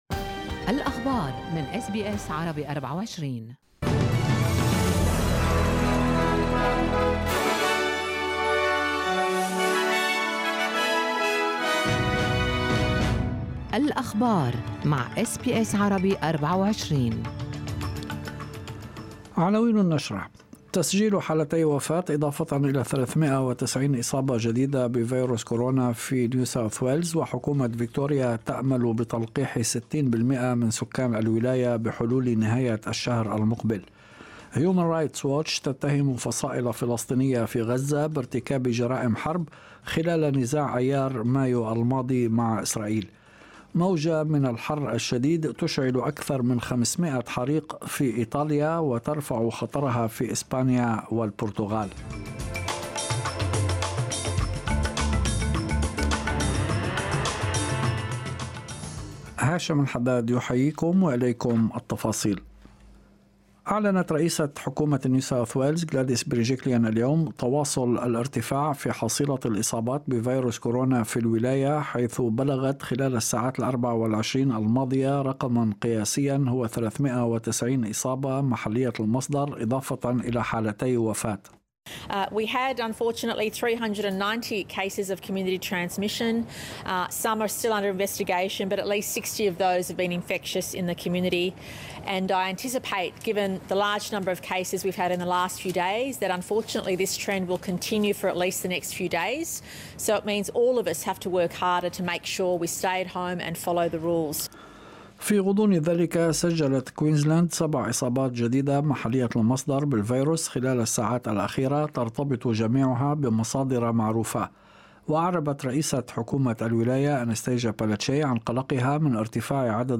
نشرة أخبار المساء 13/8/2021